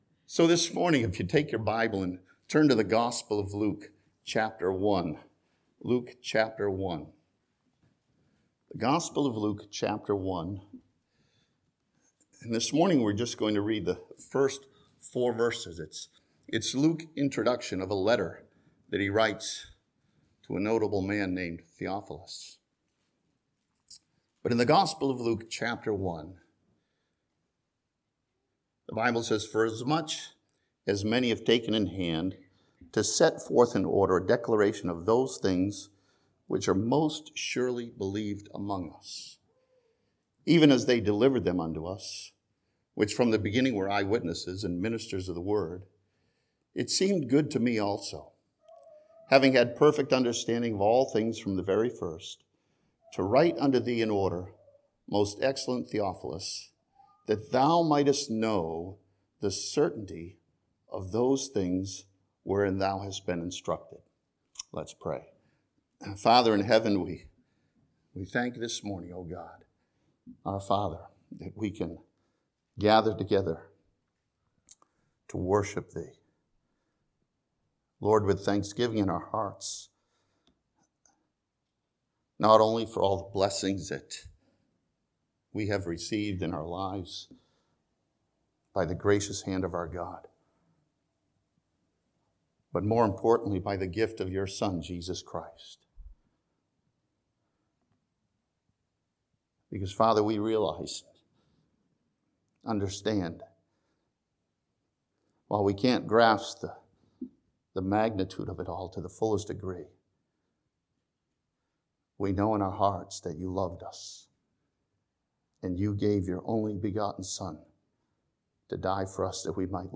This sermon from Luke chapter one sees important characteristics in both Luke the physician and Jesus Christ.